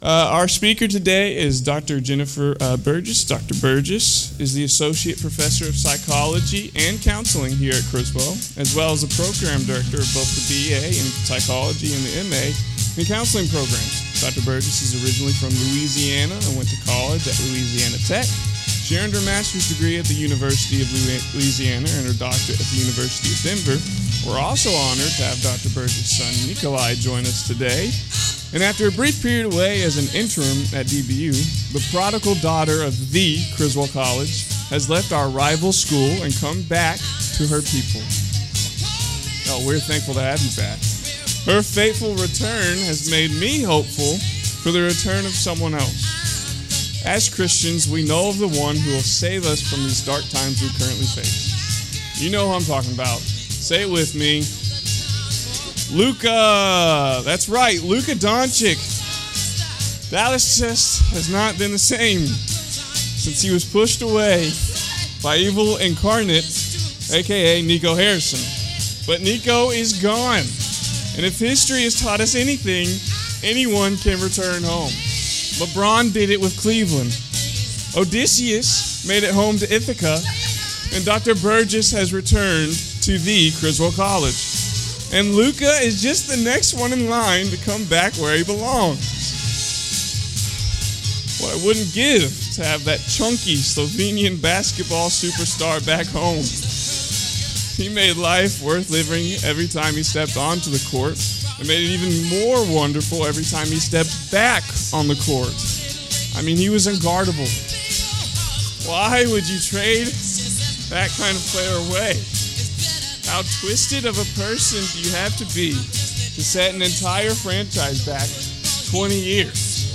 Criswell College Wednesdays Chapel.